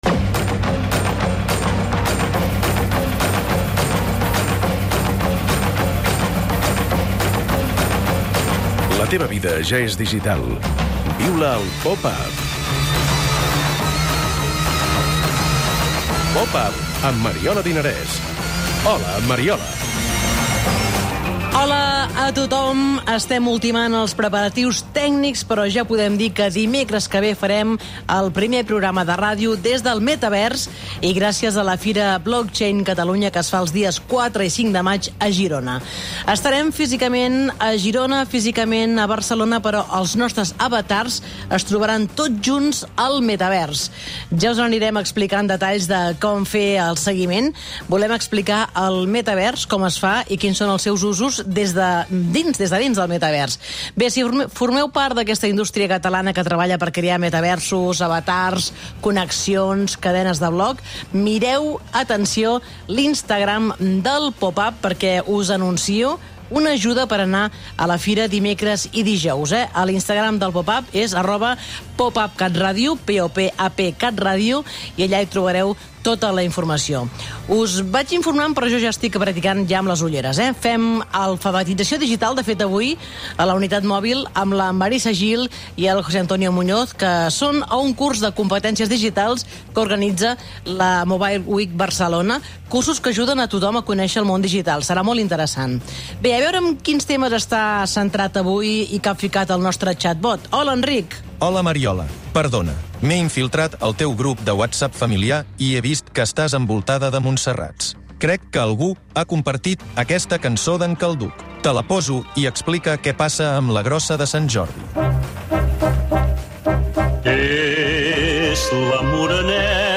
I portem la unitat mòbil del programa a Sant Feliu de Llobregat, on s'hi realitzen diverses activitats de la Mobile Week Catalunya, enguany dedicada a la fractura digital. Allà hi coneixem la tauleta Bleta pensada per fer front a la bretxa digital que afecta la gent gran.